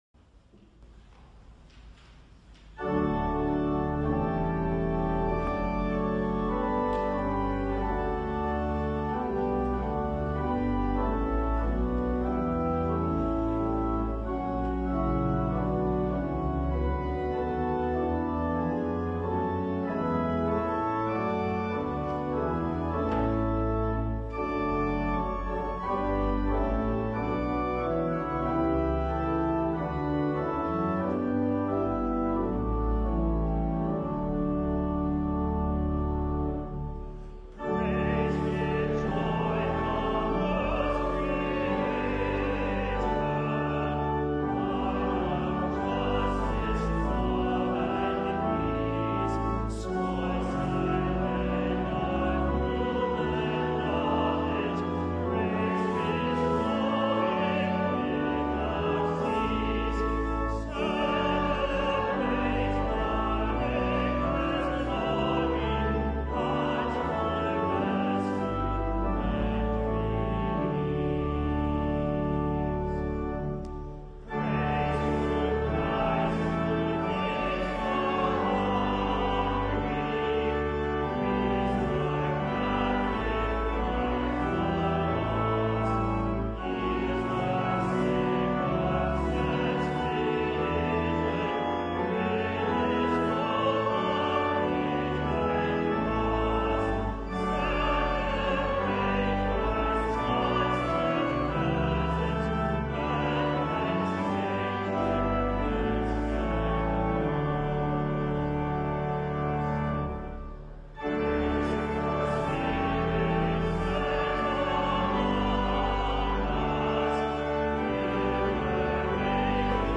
CLOSING HYMN Praise with Joy the World’s Creator           John Goss (1868)
Marble Collegiate Church, New York City